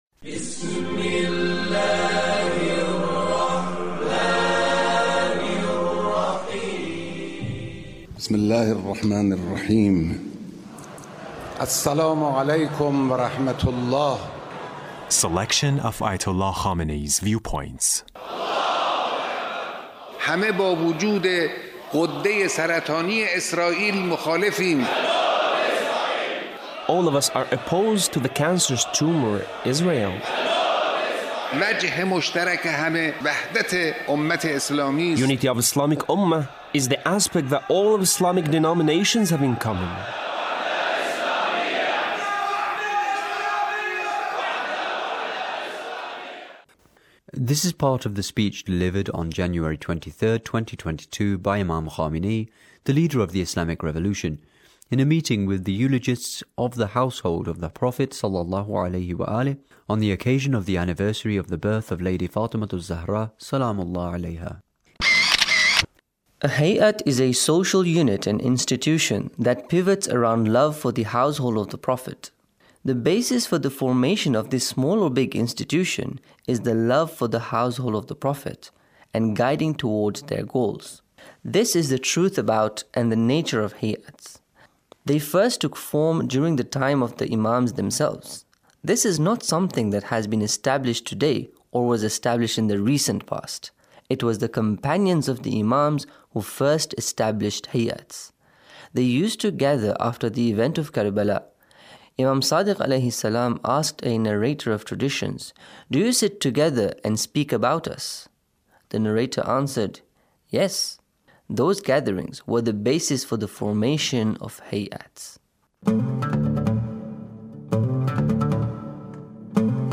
Leader's Speech on a Gathering with eulogists of the Household of the Prophet